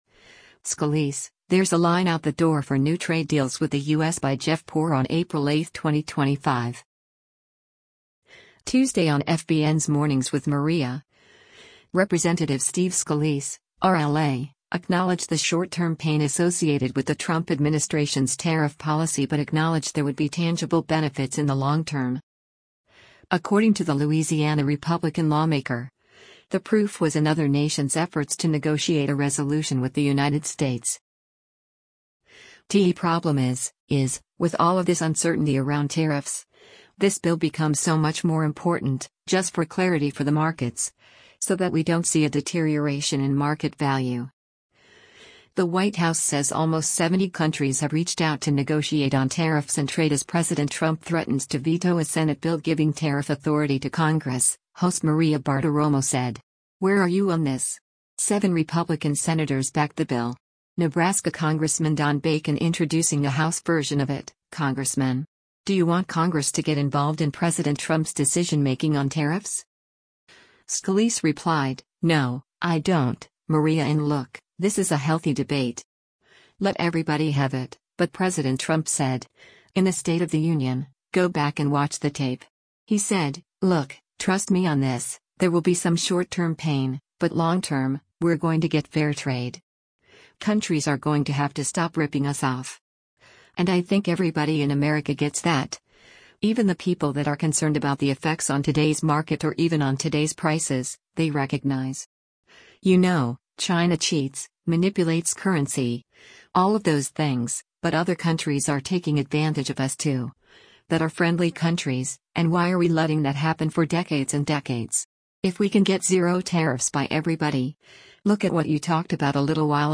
Tuesday on FBN’s “Mornings with Maria,” Rep. Steve Scalise (R-LA) acknowledged the short-term pain associated with the Trump administration’s tariff policy but acknowledged there would be tangible benefits in the long term.